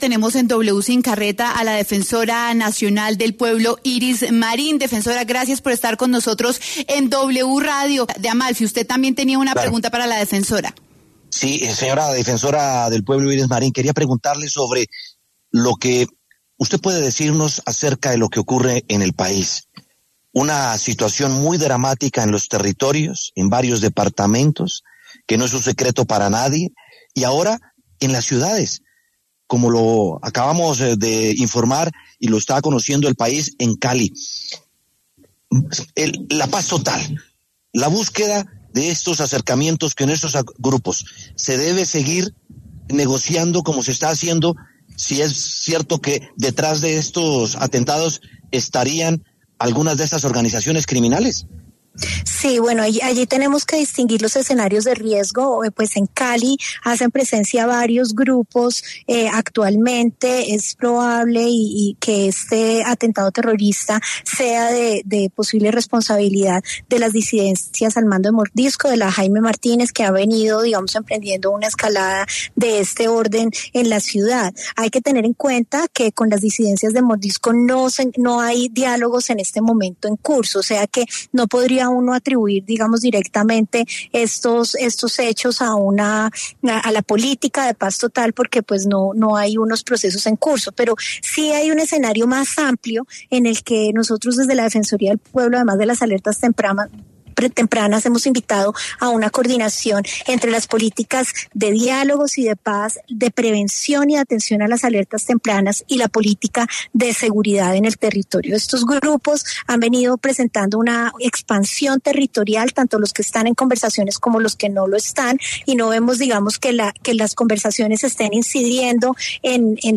Iris Marín, defensora del pueblo, estuvo en W Sin Carreta tras los hechos violentos en el país.